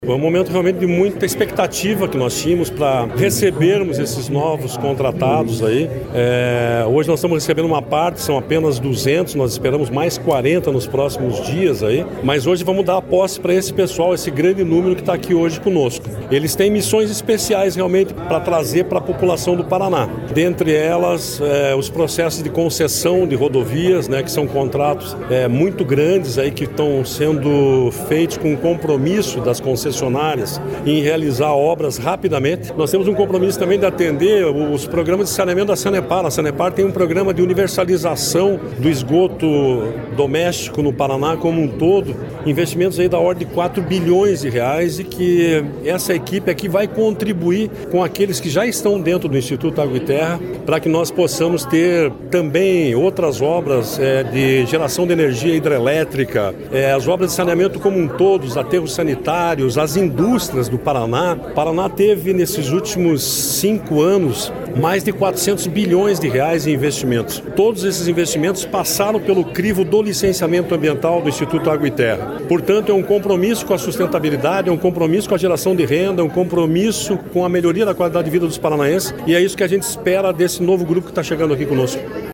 Sonora do diretor-presidente do IAT, Everton Souza, sobre a posse de 200 novos servidores no Instituto